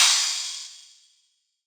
DDK1 OPEN HAT 2.wav